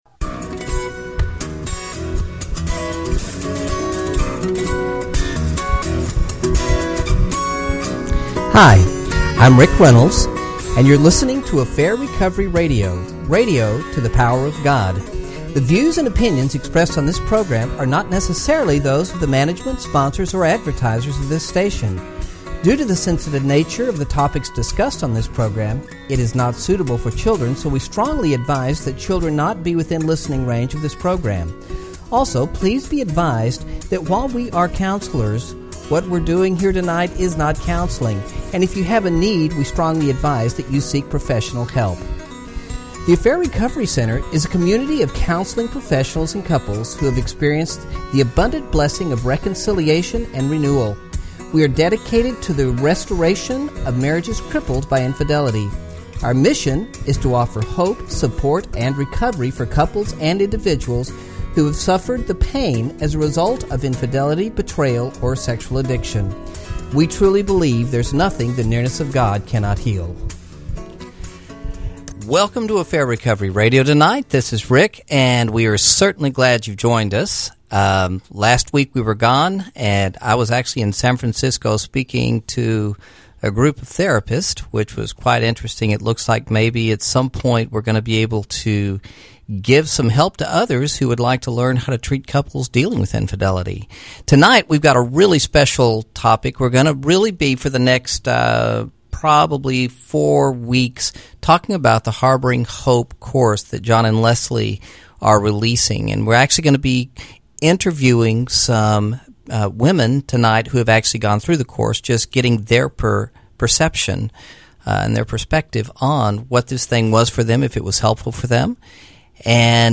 So tonight he interviews 3 people who have completed the Harboring Hope course in Austin.